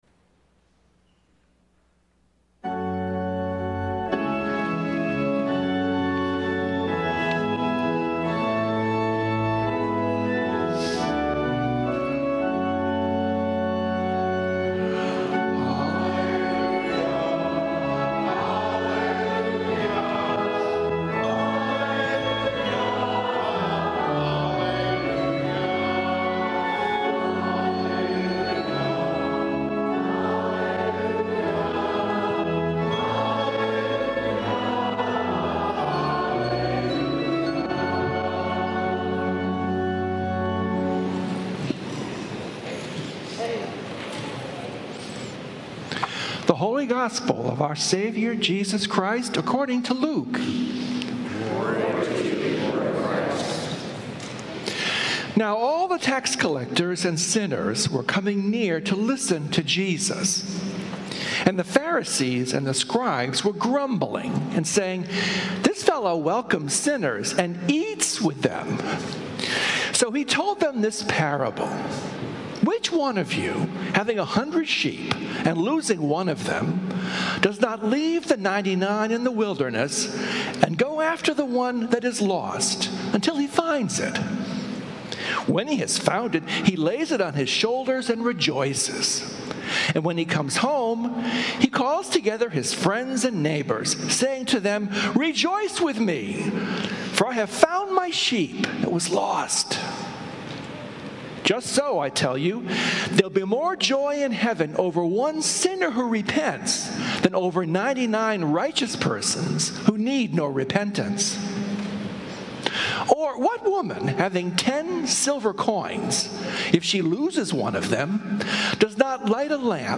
Sermons from St. Columba's in Washington, D.C.